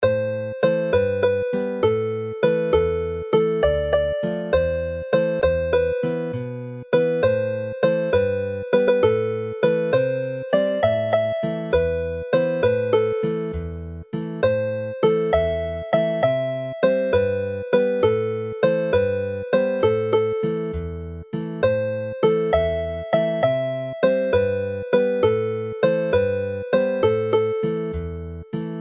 plaintive
slow jig